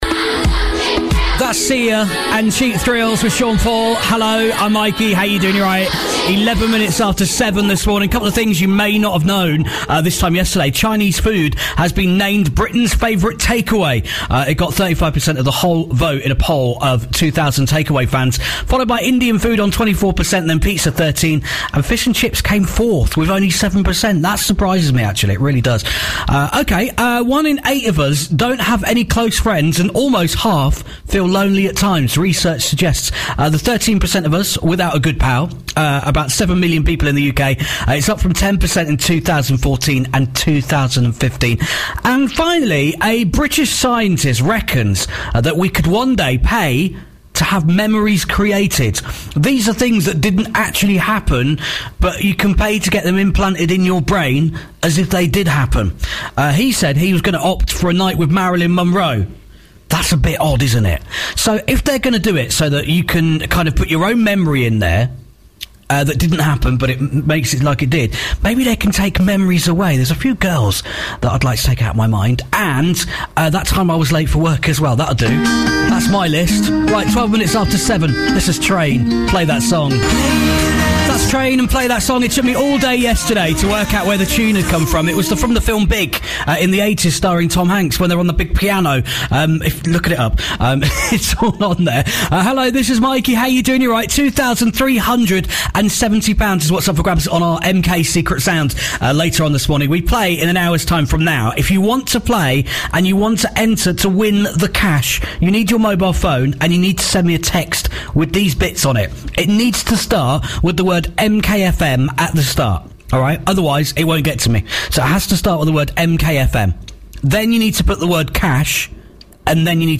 Aircheck March 1st 2017 MKFM Breakfast